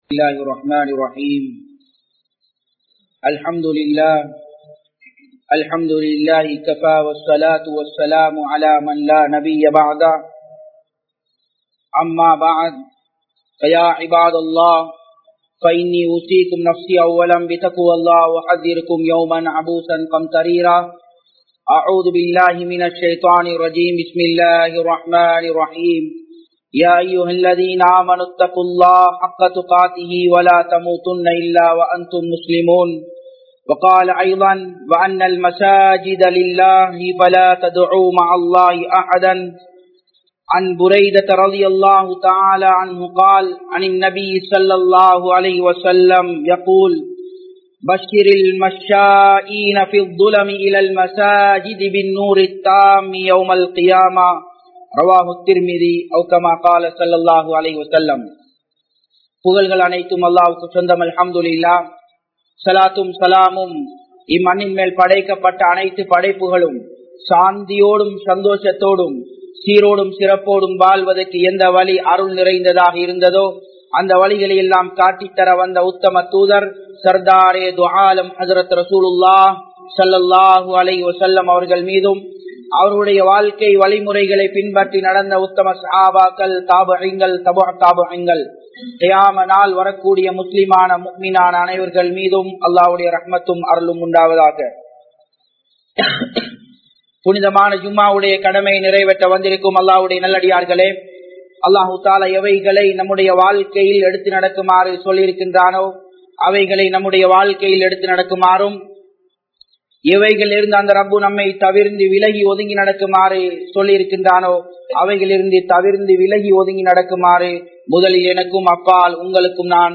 Allah Virumbum Manithan (அல்லாஹ் விரும்பும் மனிதன்) | Audio Bayans | All Ceylon Muslim Youth Community | Addalaichenai